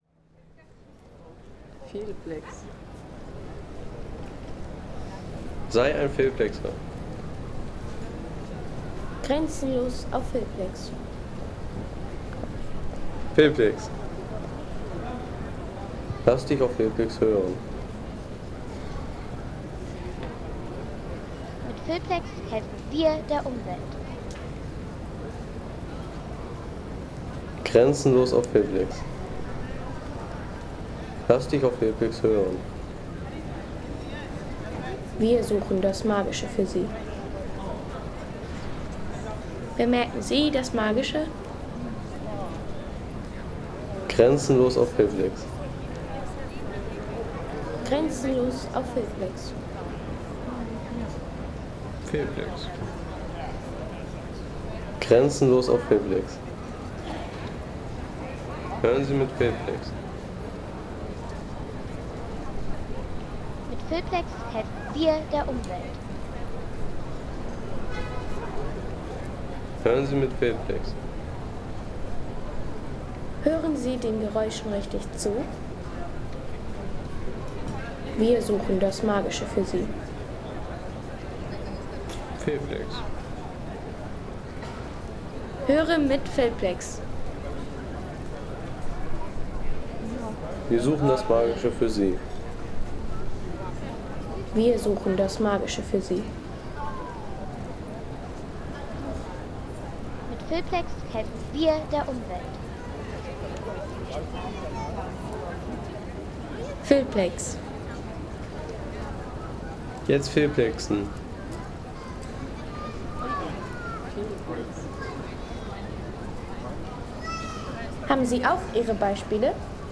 Stadtmoment Wien: Mittagsklänge am Leopoldsbrunne